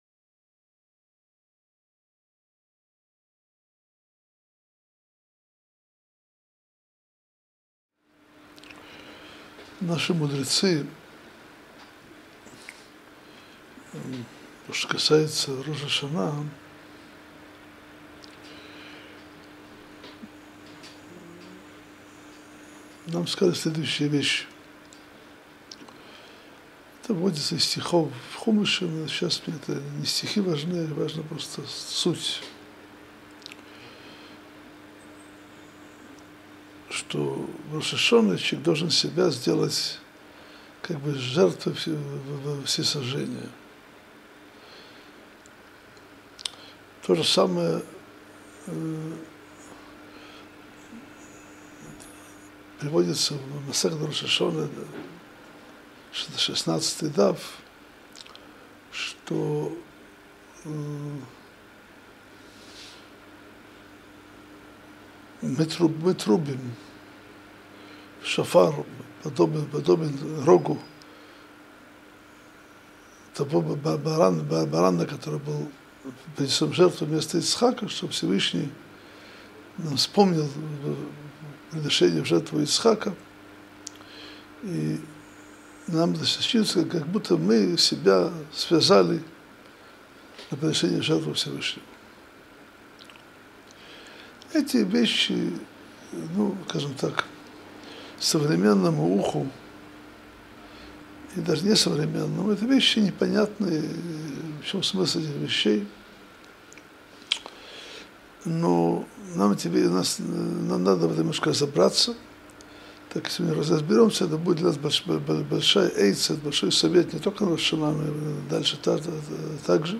Содержание урока: Зачем трубят в шофар в Рош а-Шана?